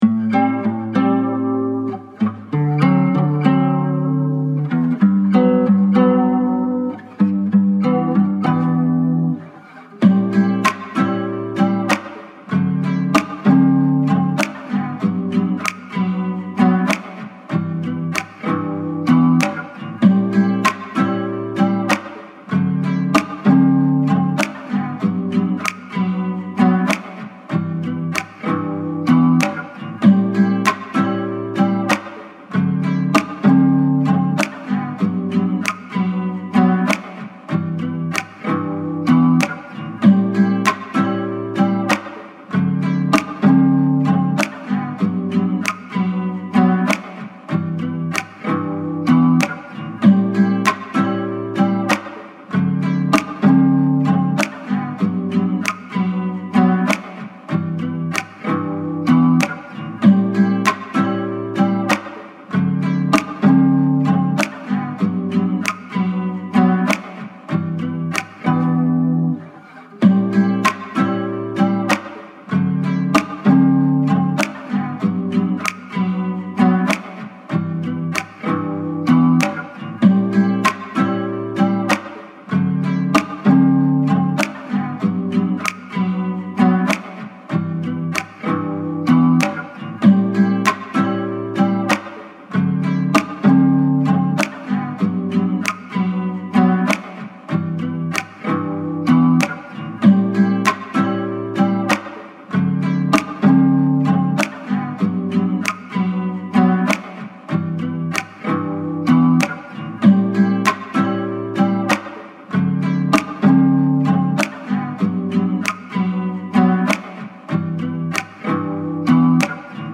90s, R&B
Bb Min